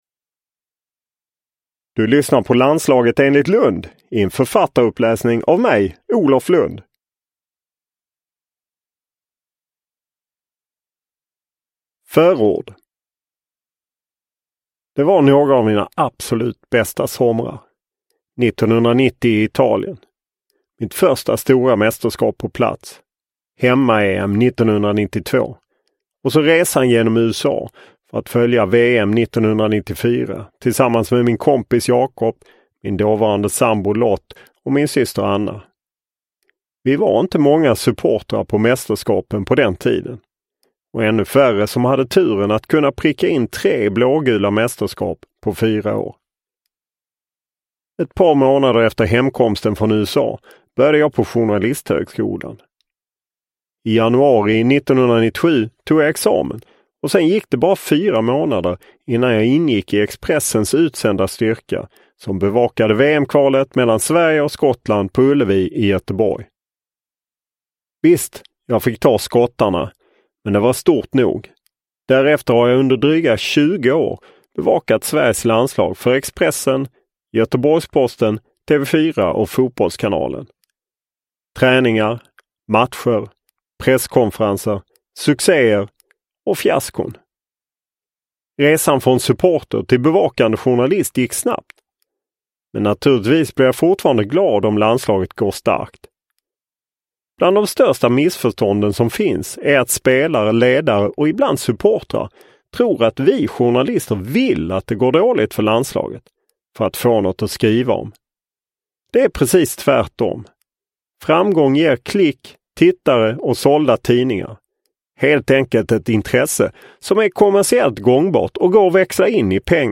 Landslaget enligt Lundh – Ljudbok – Laddas ner
Uppläsare: Olof Lundh